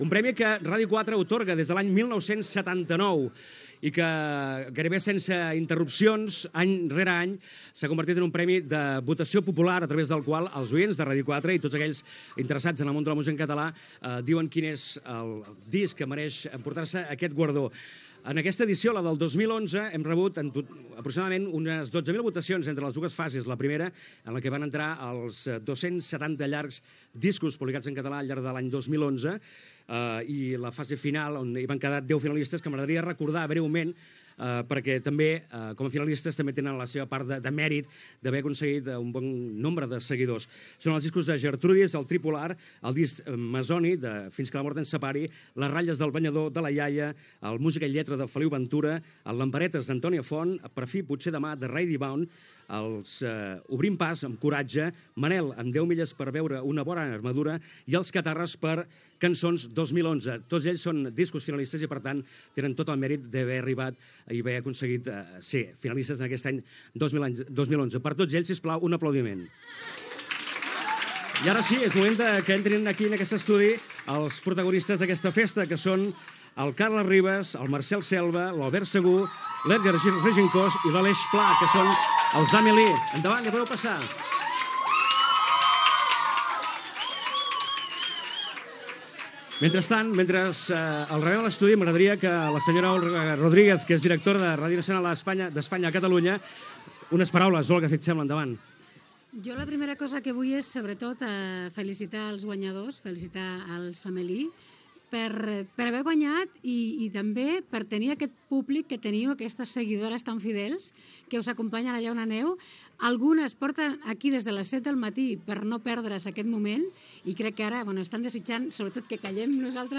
Transmissió del lliurament del Disc català de l'any 2011.
Musical